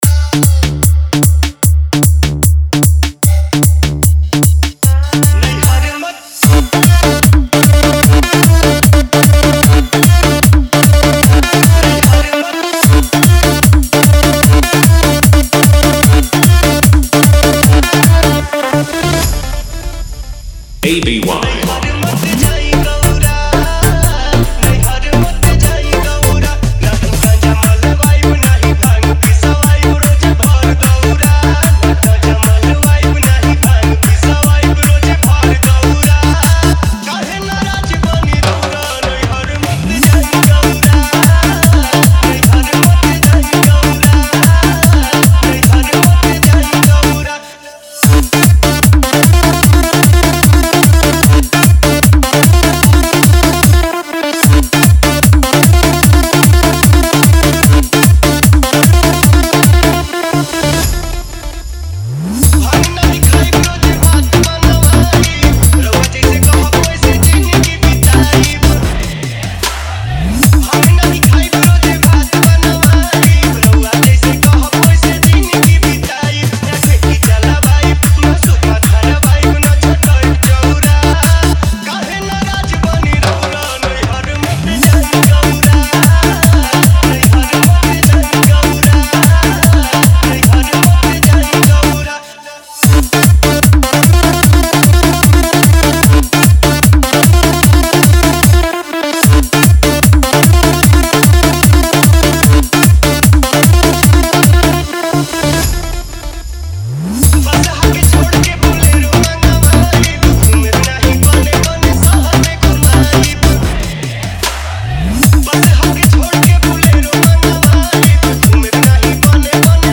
[Background: हल्की धुन और शंख की आवाज]
[Drop: Heavy Bass + Trishul FX + EDM Sawan Drop]